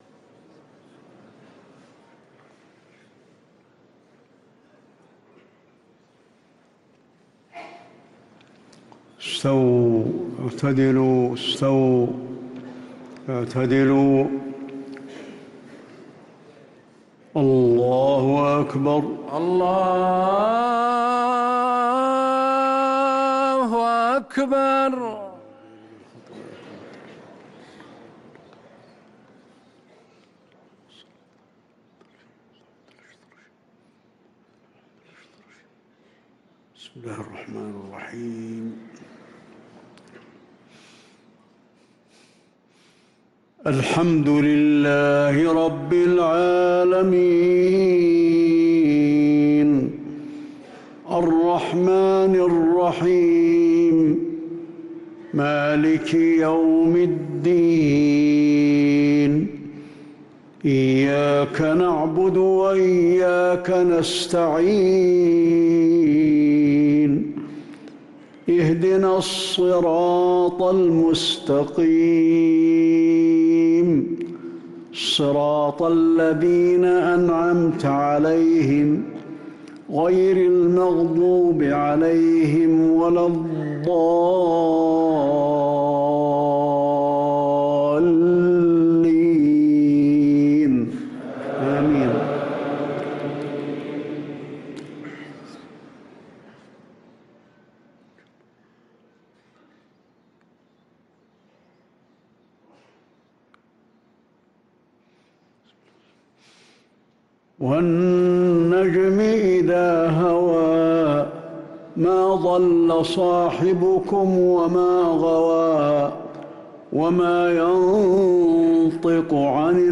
صلاة العشاء للقارئ علي الحذيفي 10 شعبان 1444 هـ
تِلَاوَات الْحَرَمَيْن .